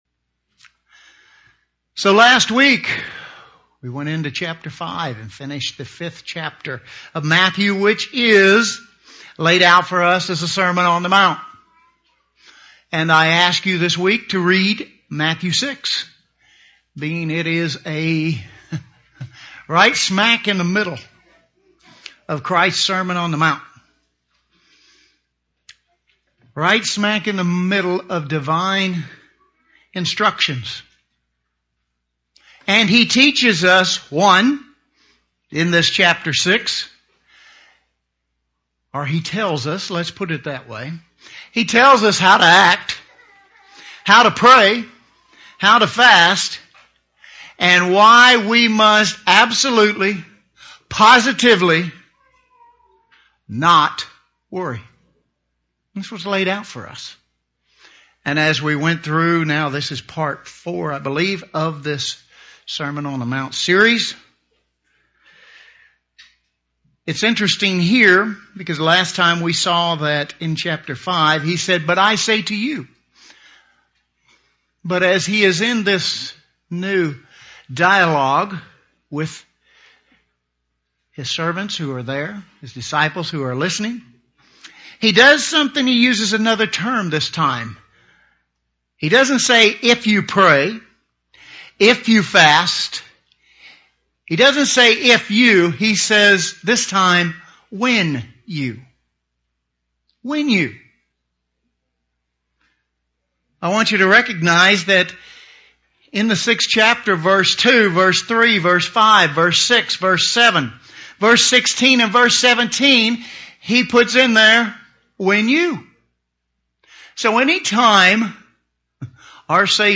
Christ used the words "when you..." several times in His sermon. This sermon explores His use of this phrase.